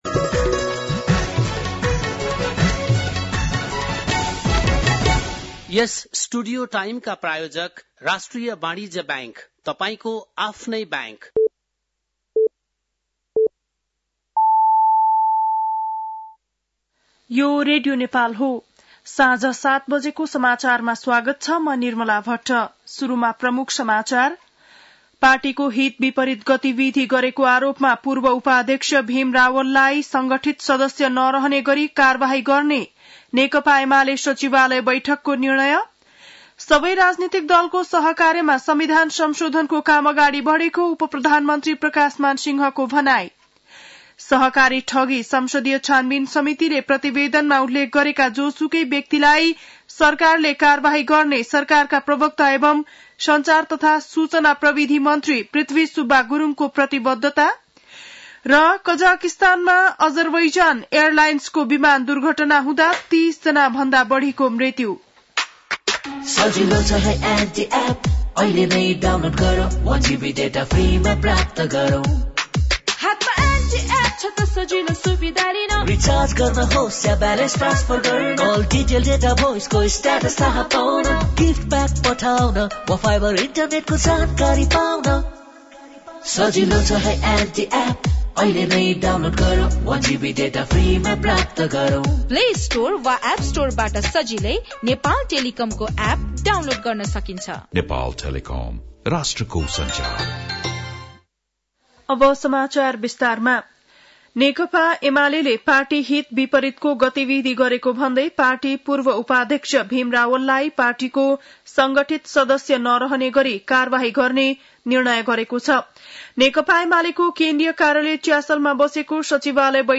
बेलुकी ७ बजेको नेपाली समाचार : ११ पुष , २०८१
7-PM-Nepali-News-9-10.mp3